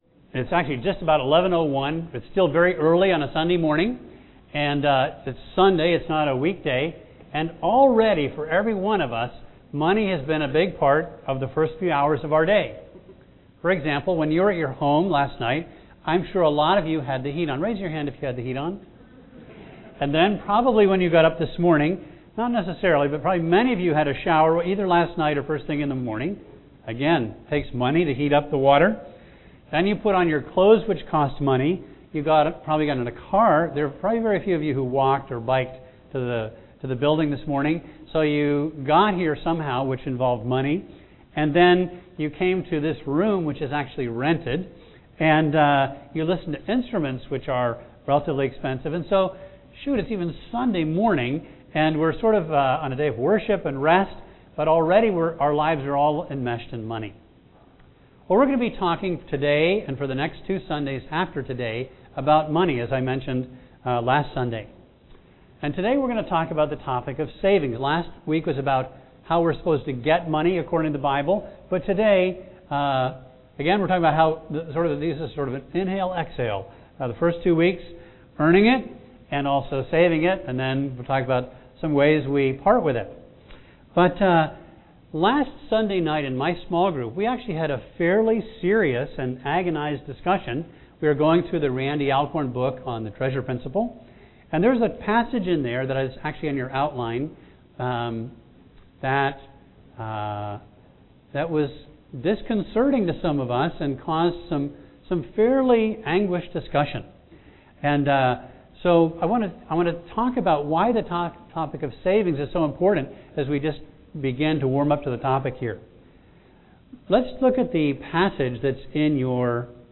A message from the series "Money."